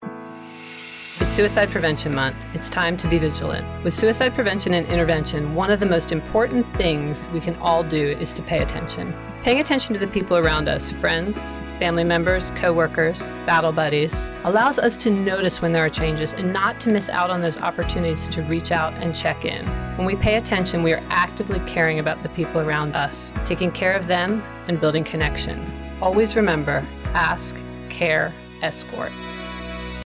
30 second radio spot for the Army Substance Abuse Program for the month of September 2024.